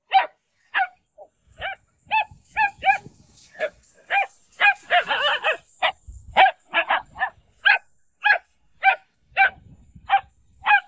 dog
bark_27005.wav